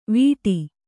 ♪ vīṭi